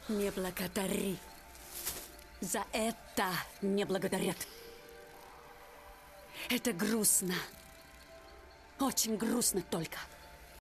Ахахах sound effects free download